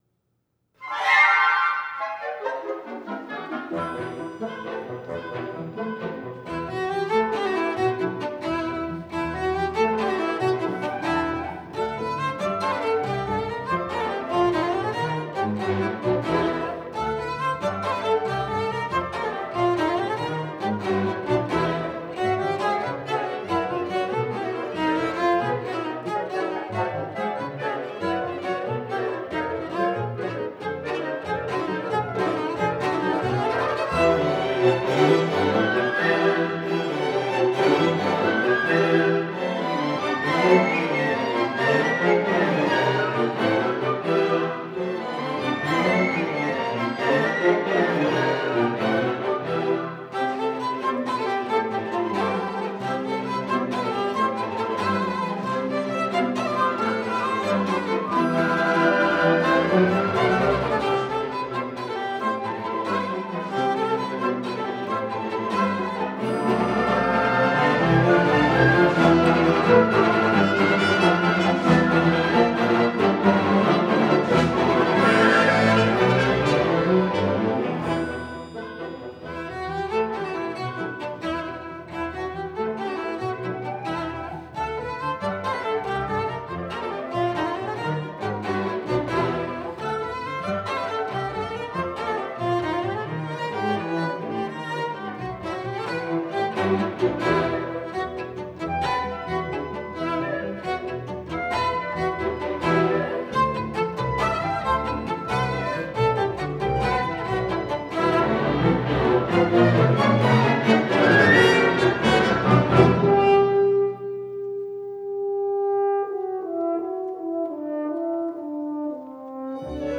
Orchestral / Cello Concerto